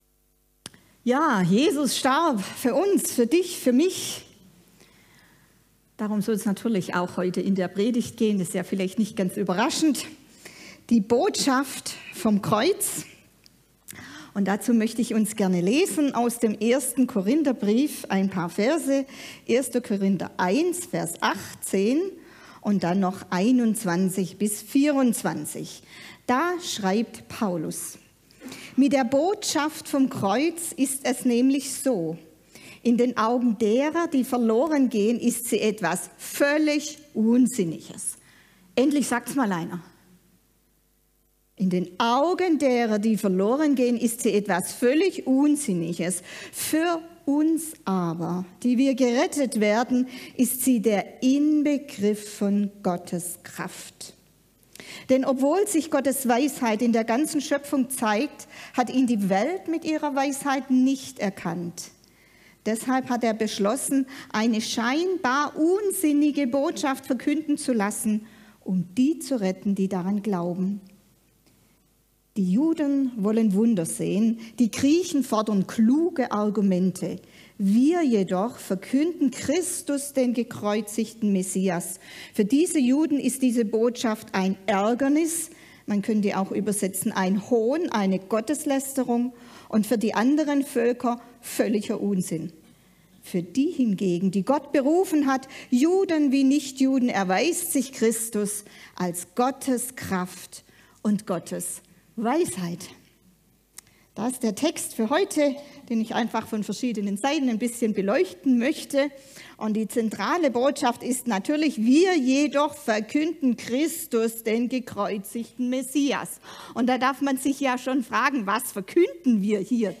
Passage: 1. Korinther 1, 18-24 Dienstart: Gottesdienst Gottesdienst